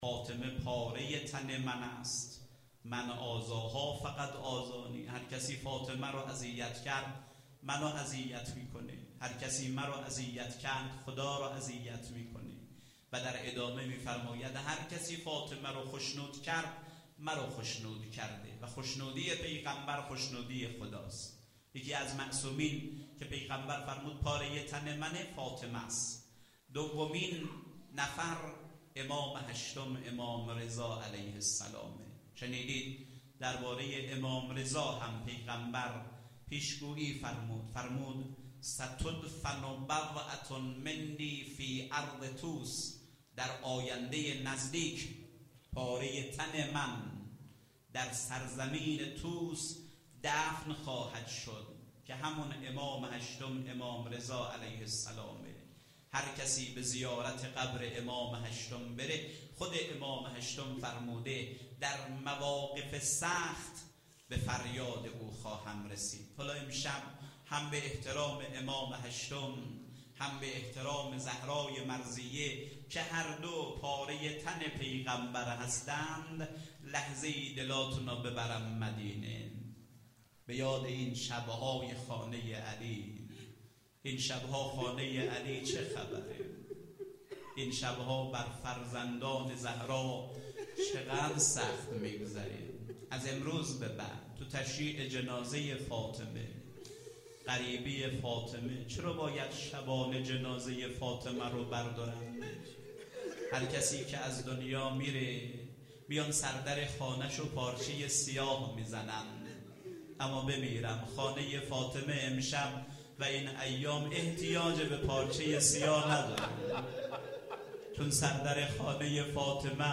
روضه حضرت زهرا س(شب اول فاطمیه93)